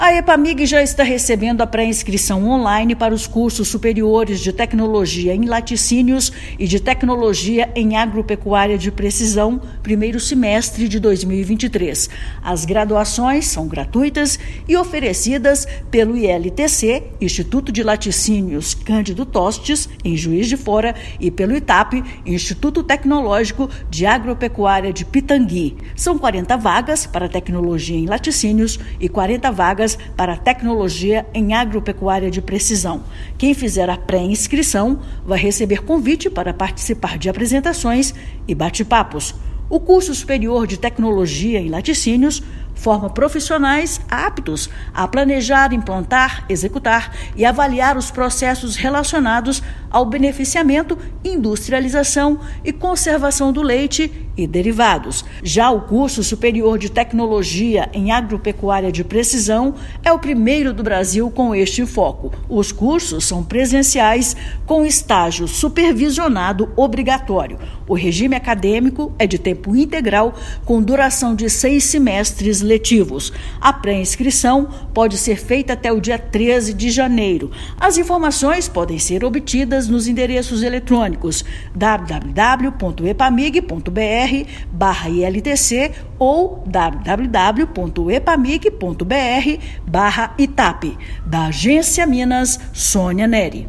[RÁDIO] Epamig abre pré-inscrição para os cursos de graduação 2023
A Empresa de Pesquisa Agropecuária de Minas Gerais (Epamig) já está recebendo a pré-inscrição on-line para os cursos superiores de Tecnologia em Laticínios e de Tecnologia em Agropecuária de Precisão 1º semestre de 2023. Ouça matéria de rádio.